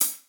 Closed Hats
Boom-Bap Hat CL 71.wav